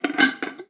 Abrir un tarro de cristal
Cocina
Sonidos: Acciones humanas
Sonidos: Hogar